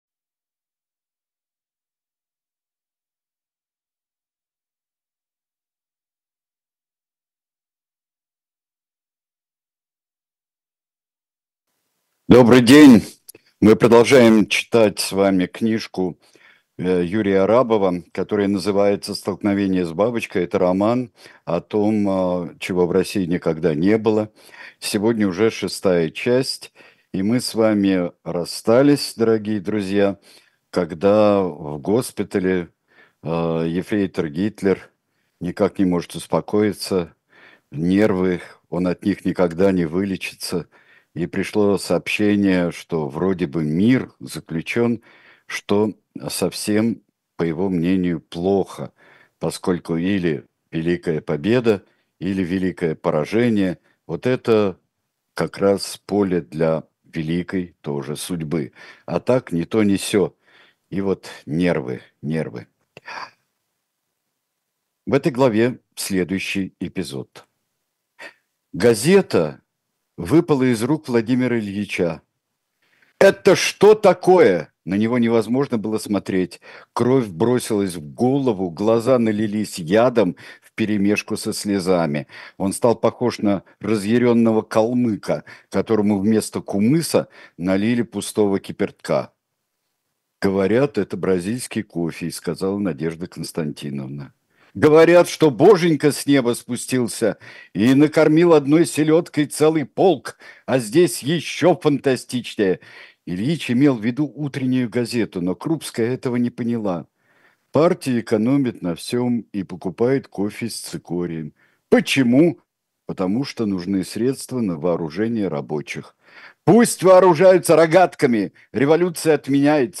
Сергей Бунтман читает роман Юрия Арабова
«Столкновение с бабочкой» — часть 6. Читает Сергей Бунтман